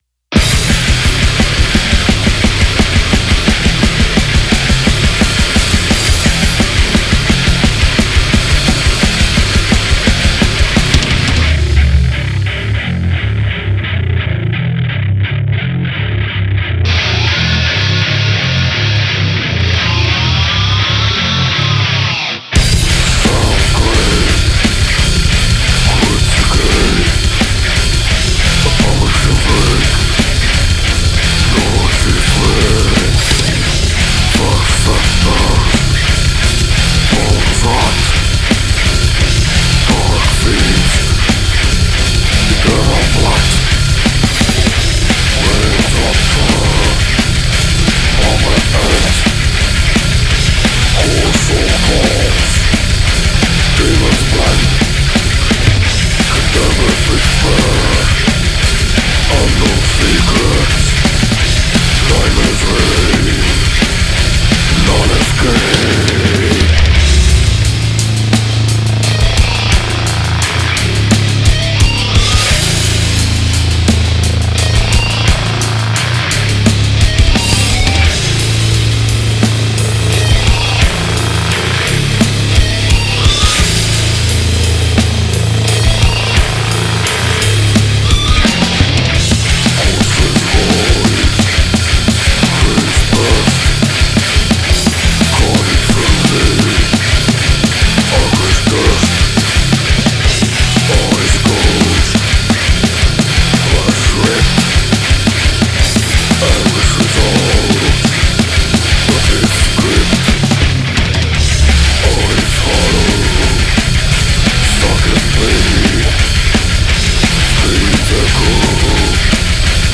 v2, ein unnötiger low cut aufm Master war noch an https